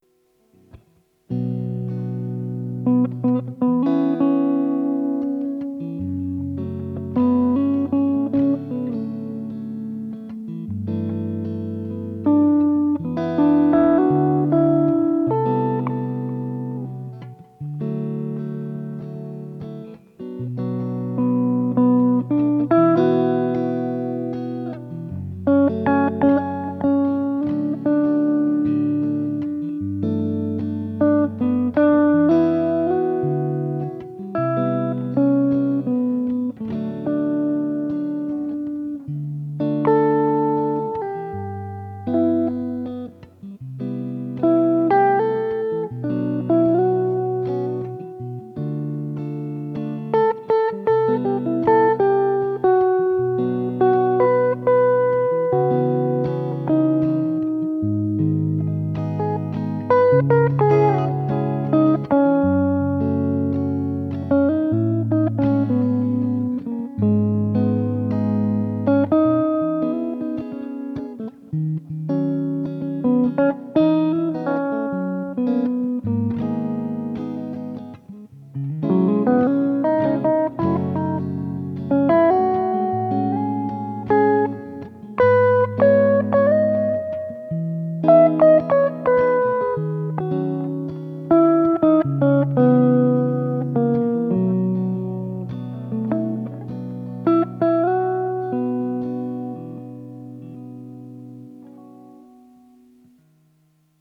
cover 1)